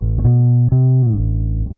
BASS 9.wav